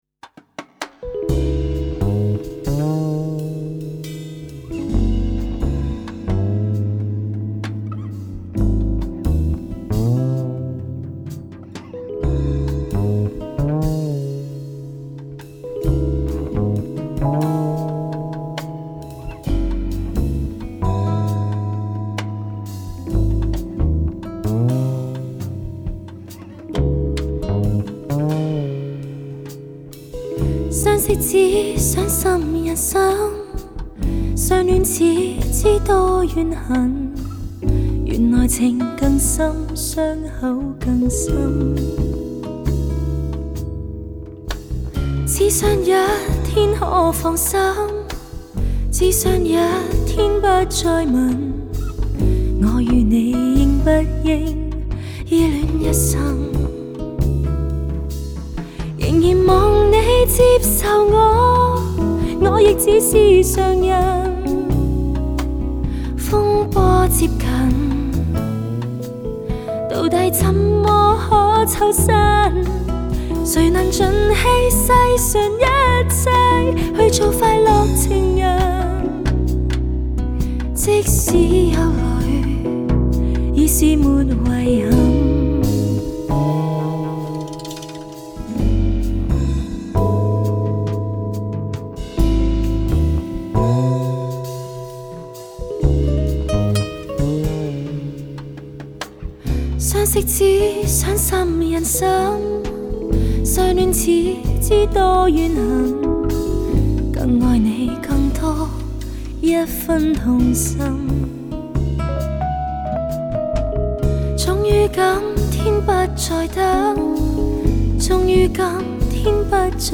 Жанр: Cantopop